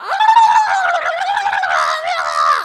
Witch Death Sound
horror